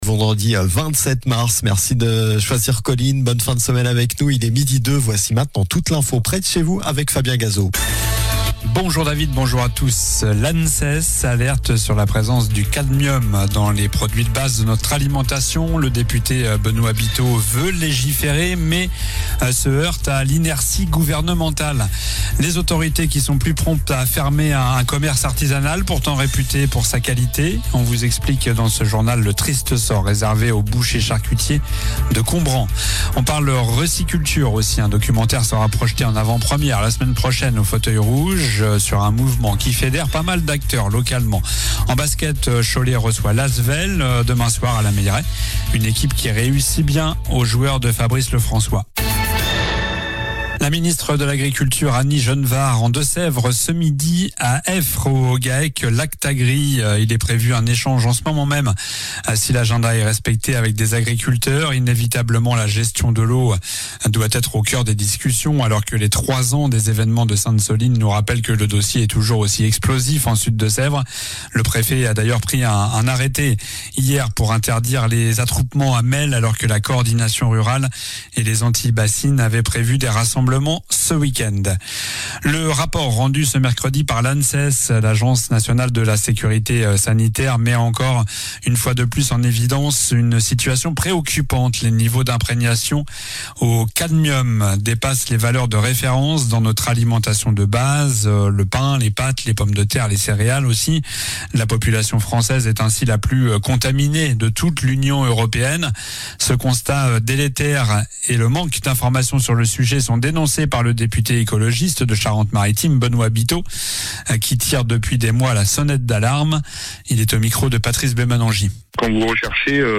Journal du vendredi 27 mars (midi)